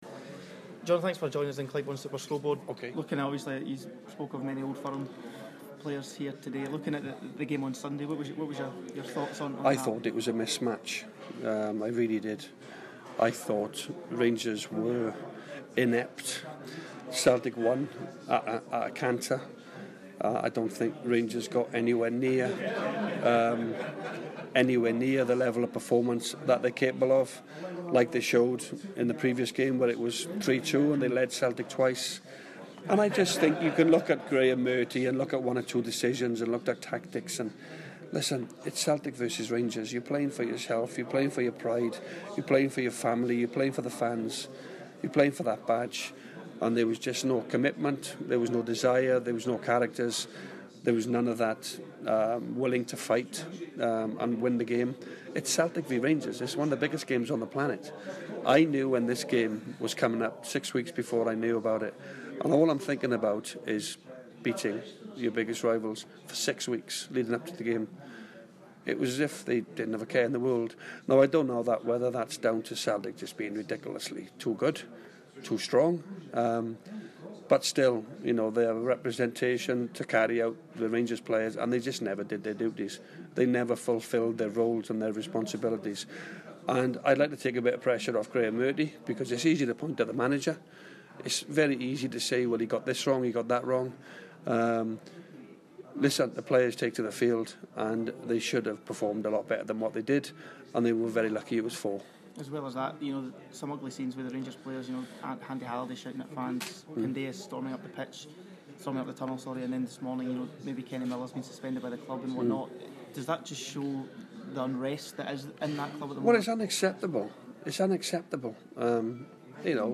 Former Celtic striker sits down with our sports reporter